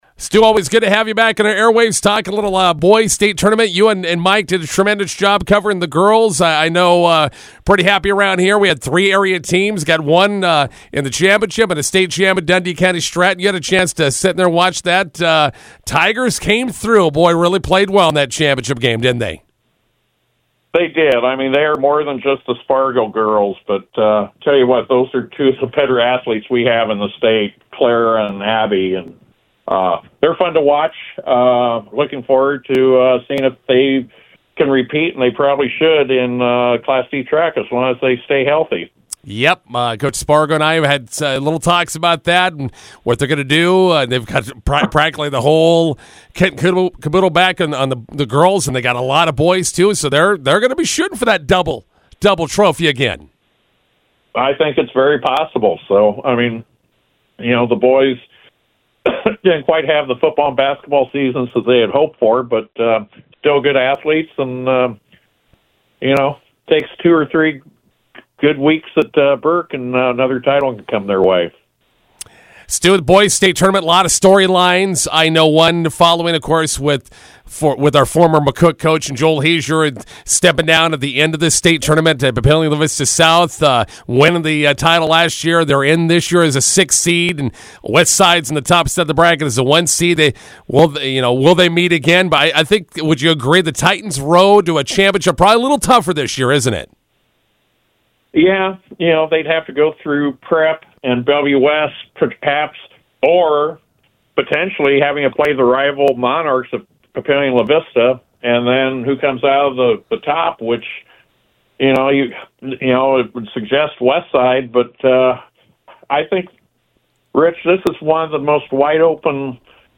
INTERVIEW: The Nebraska boys state basketball tournament begins on Wednesday in Lincoln.